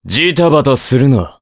Crow-Vox_Skill2_jp.wav